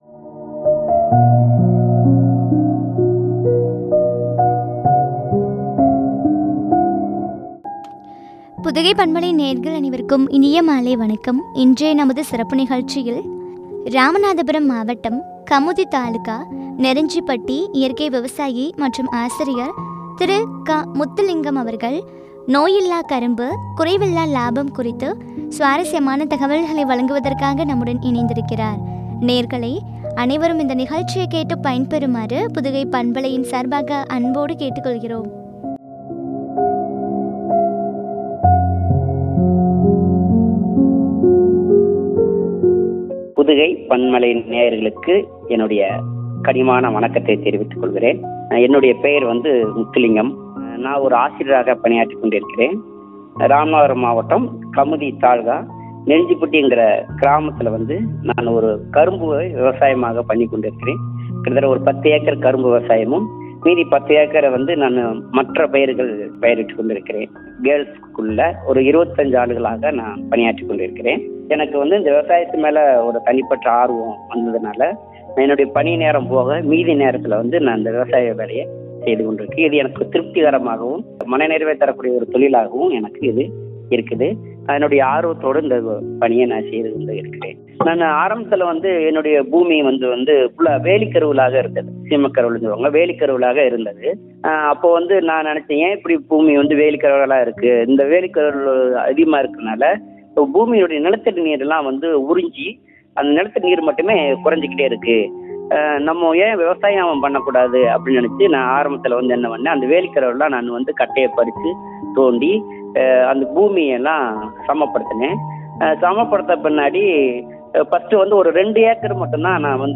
குறைவில்லா லாபம் குறித்து வழங்கிய உரையாடல்.